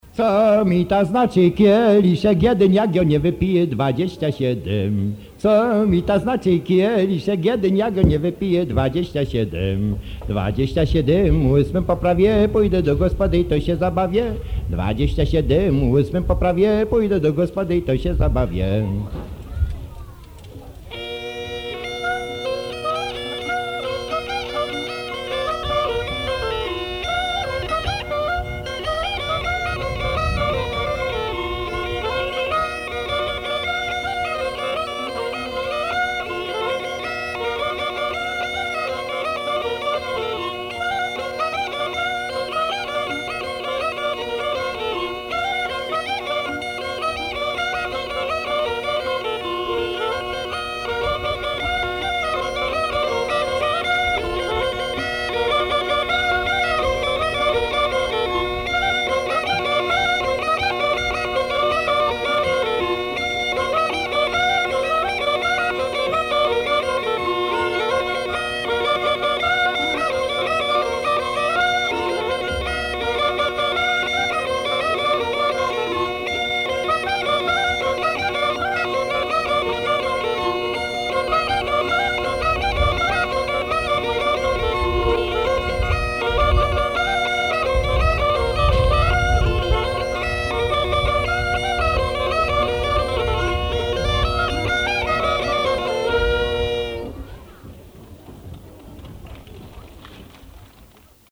Quinzième pièce - Voix, duda, clarinette